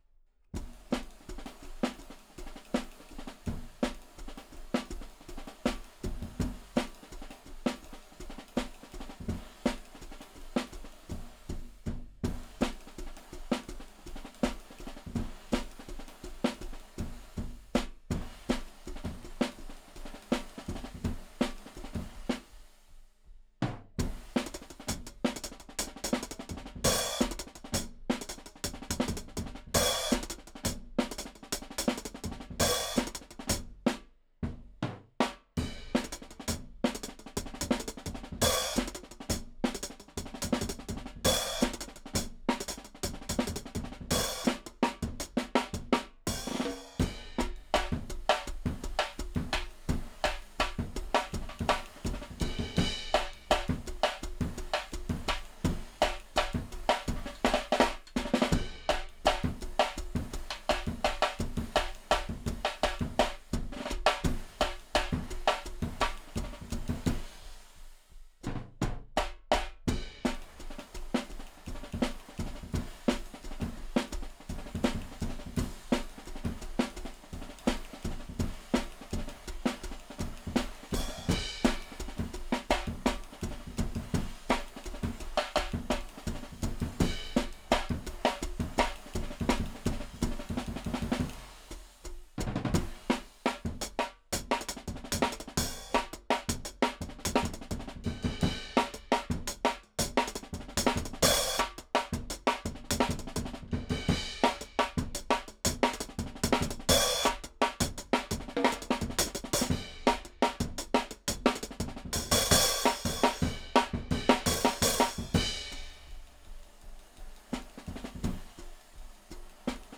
Index of /4 DRUM N BASS:JUNGLE BEATS/BEATS OF THE JUNGLE THAT ARE ANTIFUNGAL!!/RAW MULTITRACKS
HIHAT_1.wav